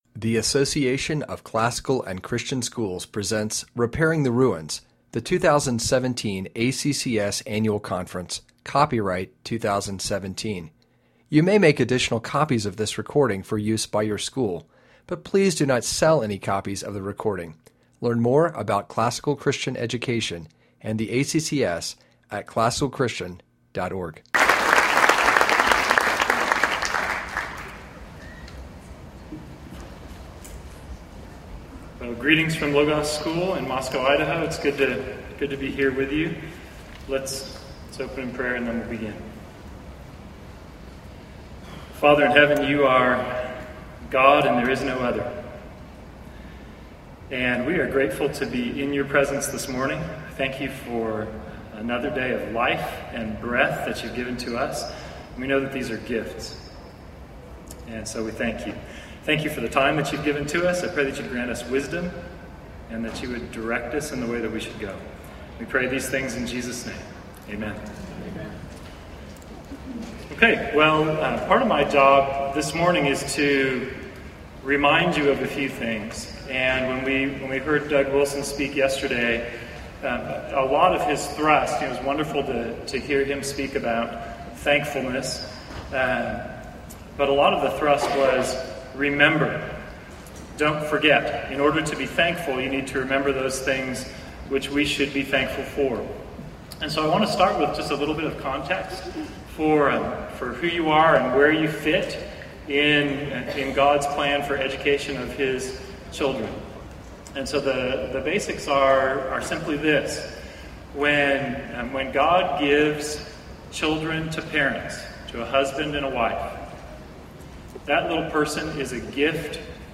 2017 Plenary Talk | 0:52:39 | All Grade Levels, Virtue, Character, Discipline